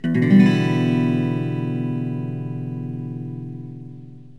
Em7.mp3